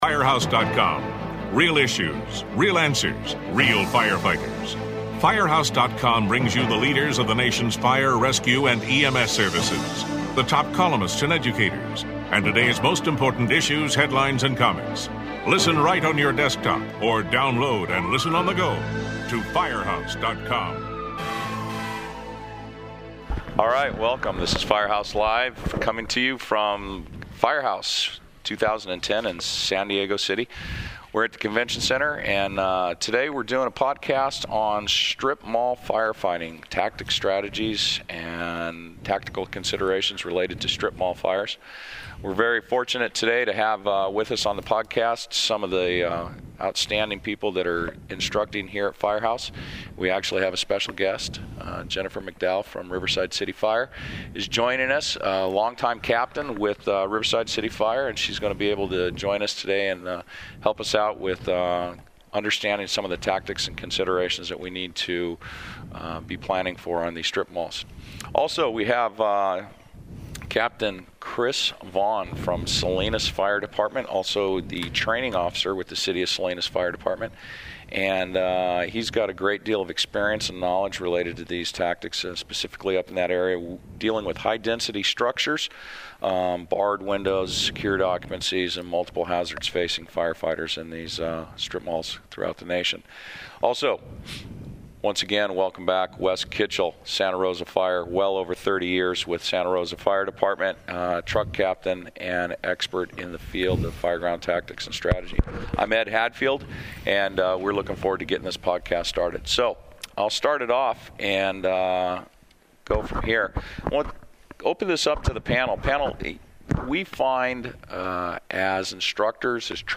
Listen as veteran firefighters and officers discuss tactical and command priorities for strip mall fires.